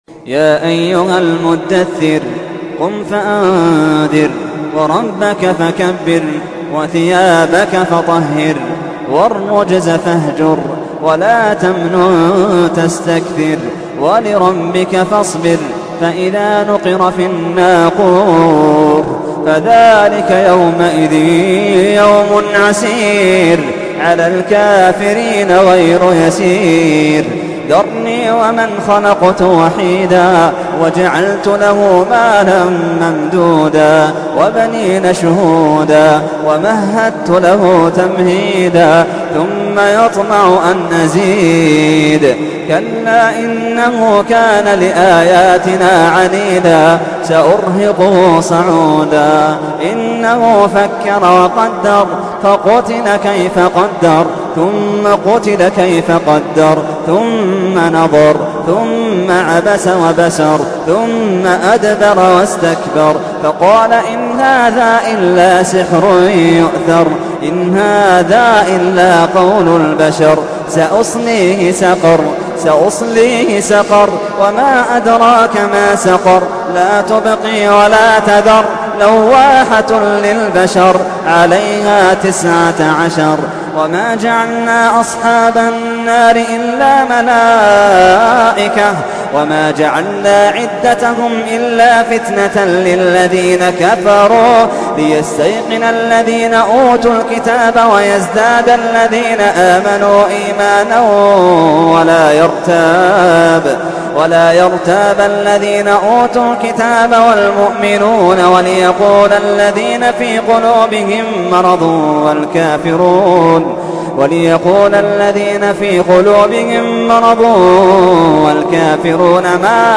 تحميل : 74. سورة المدثر / القارئ محمد اللحيدان / القرآن الكريم / موقع يا حسين